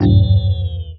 poweroff.wav